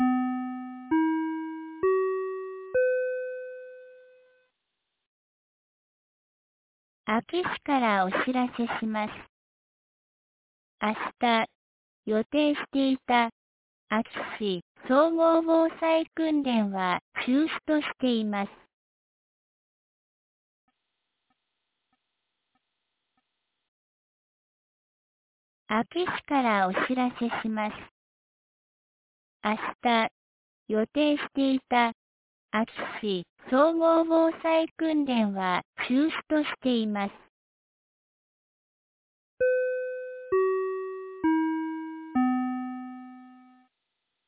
2024年09月02日 09時56分に、安芸市より全地区へ放送がありました。